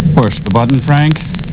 Below is my collection of sounds from the TV show MST3K and MST3K the movie.